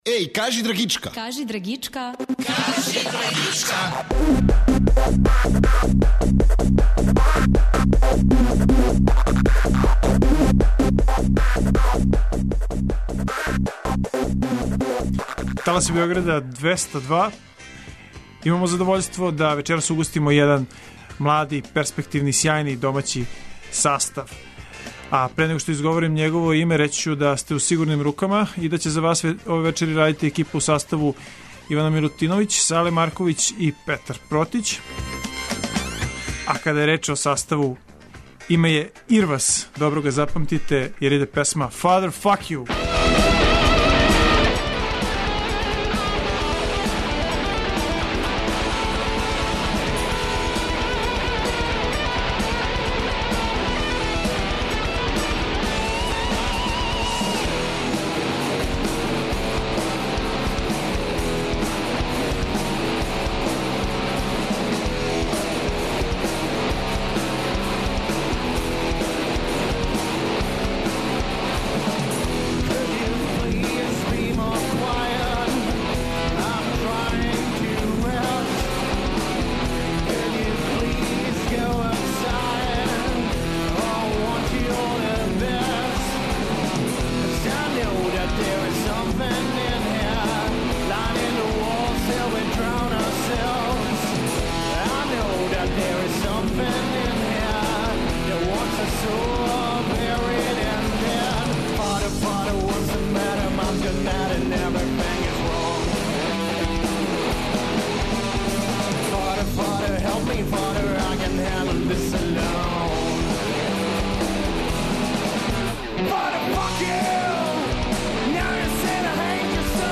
Имамо задовољство да угостимо нови и перспективни домаћи састав, ’’Ирвас’’.
Вечерас ћемо преслушавати музику коју су донели у студио и најавити предстојеће свирке.